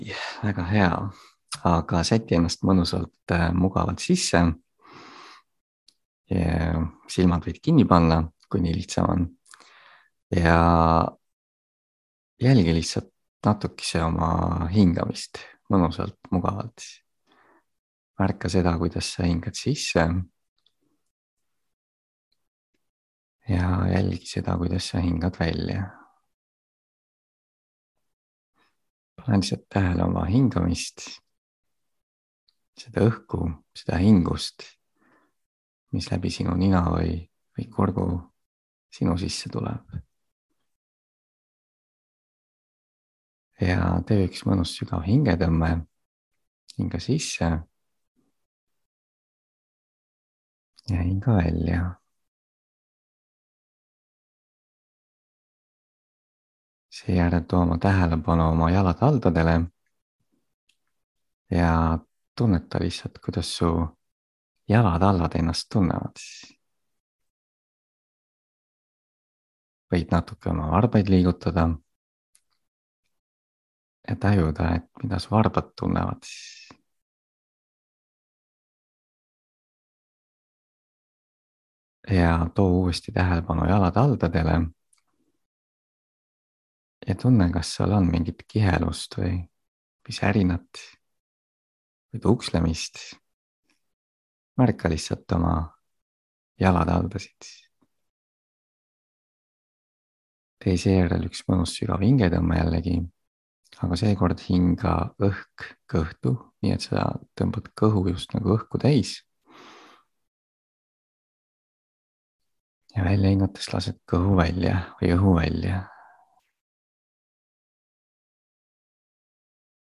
Uue aasta meditatsioon
Žanr: Meditative.
uue_aasta_meditatsioon.mp3